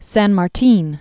(săn mär-tēn, sän), José de 1778-1850.